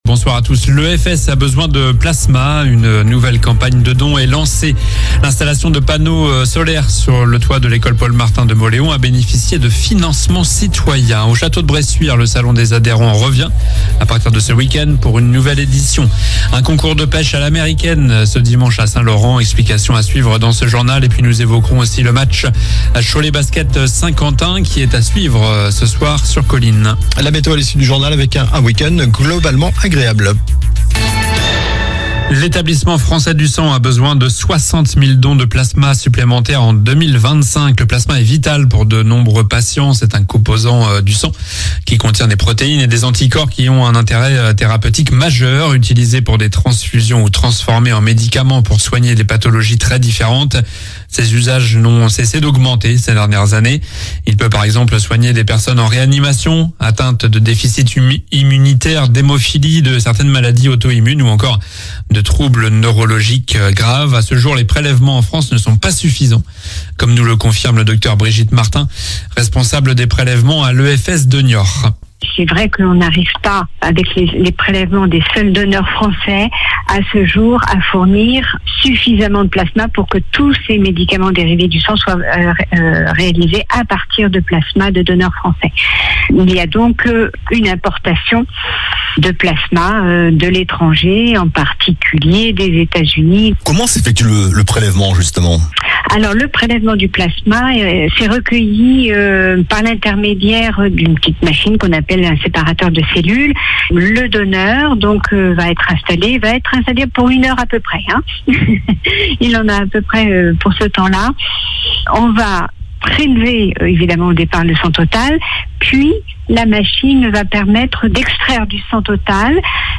Journal du vendredi 11 octobre (soir)